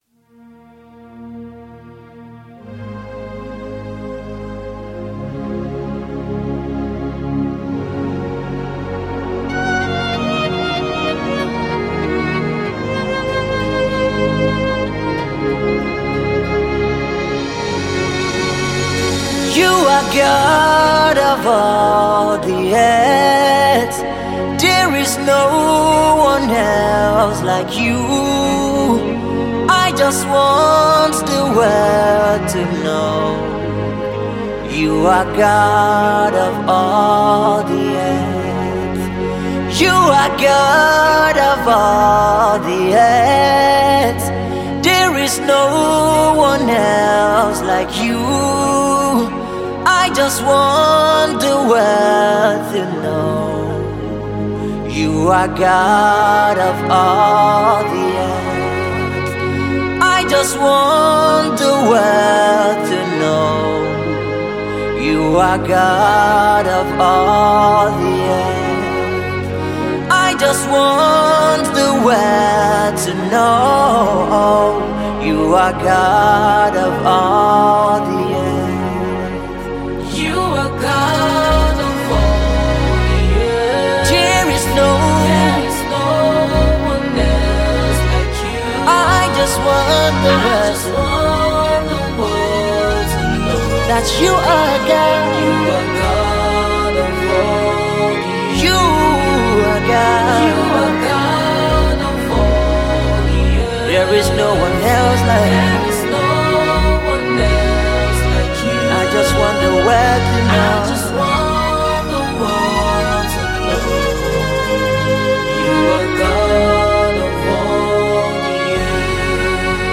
worship song
Gospel